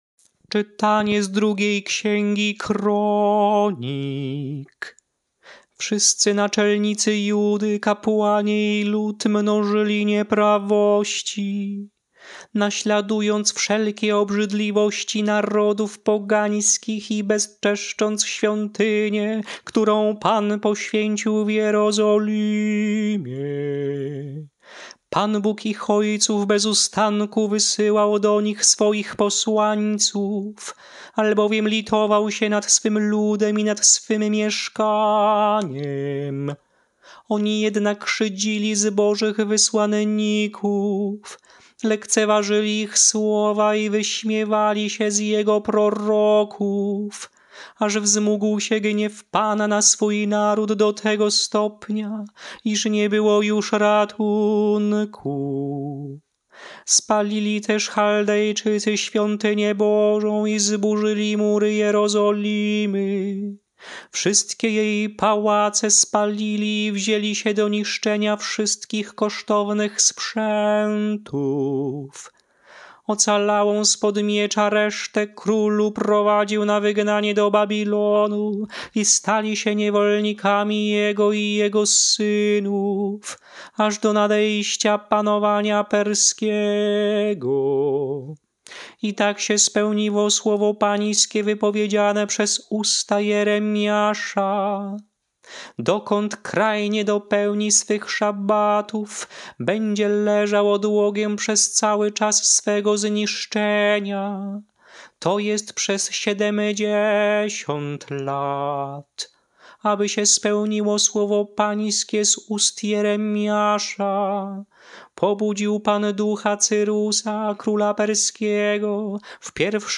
Śpiewane lekcje mszalne – IV Niedziela Wielkiego Postu
Melodie lekcji mszalnej przed Ewangelią na IV Niedzielę Wielkiego Postu: